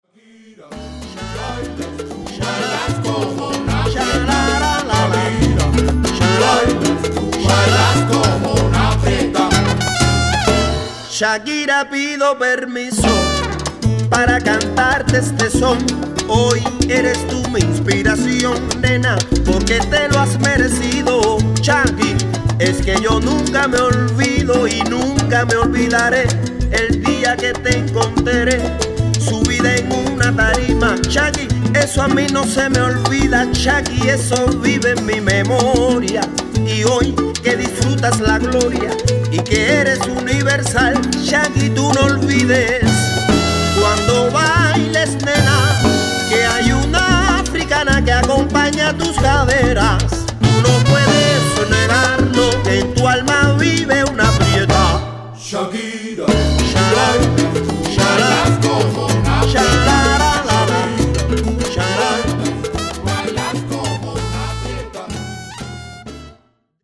traditioneller Son trifft auf den kreativsten Sänger Cubas.